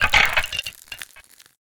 PixelPerfectionCE/assets/minecraft/sounds/mob/stray/death2.ogg at mc116